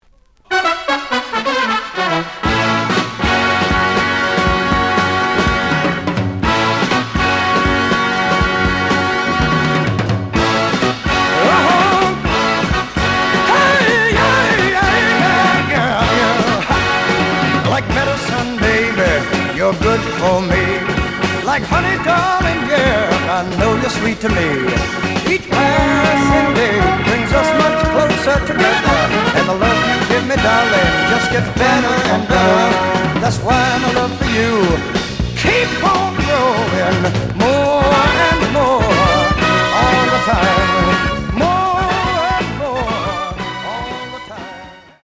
The audio sample is recorded from the actual item.
●Genre: Rock / Pop
B side plays good.)